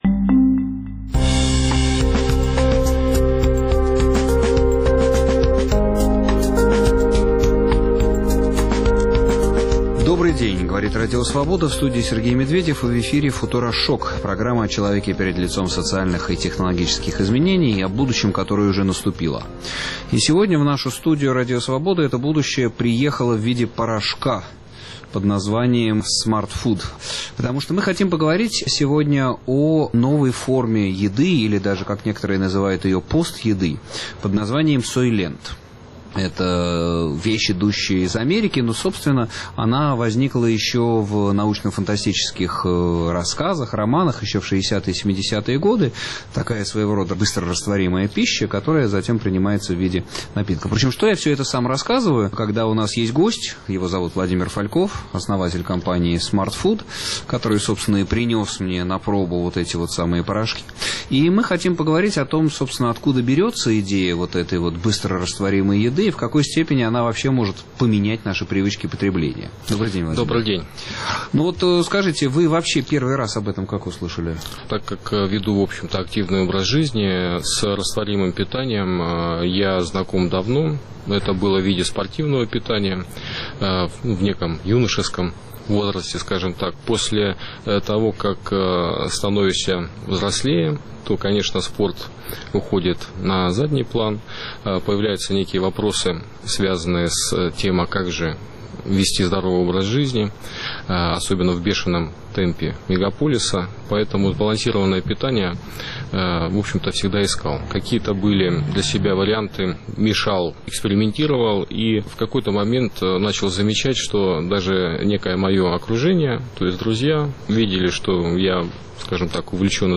Разговор о новой форме еды или, как называют ее некоторые, пост-еды под названием «сойлент».